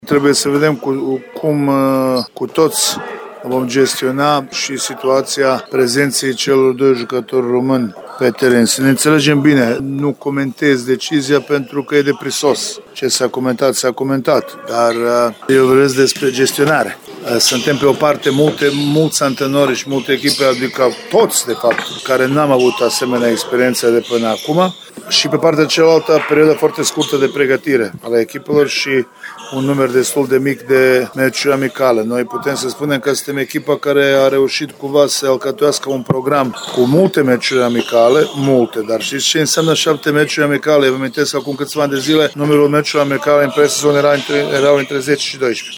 Decizia a fost comentată de doi foști selecționeri, la capătul partidei SCM Timișoara – CSM Galați 85-81.